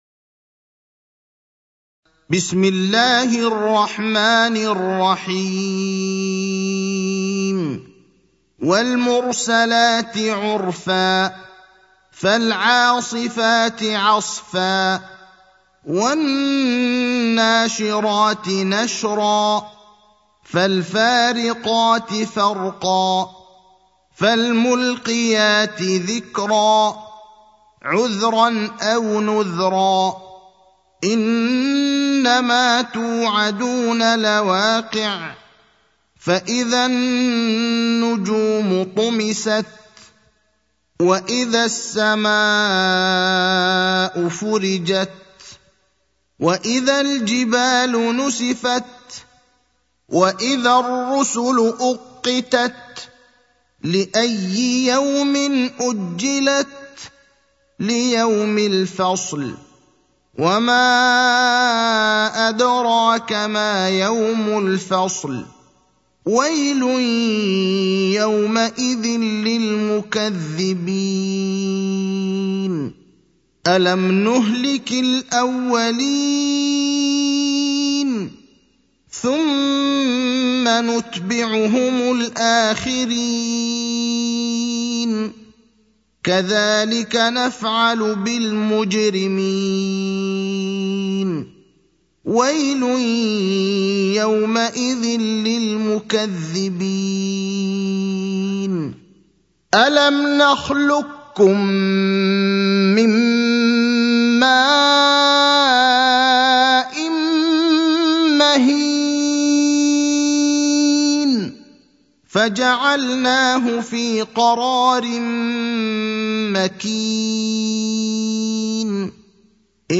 المكان: المسجد النبوي الشيخ: فضيلة الشيخ إبراهيم الأخضر فضيلة الشيخ إبراهيم الأخضر المرسلات (77)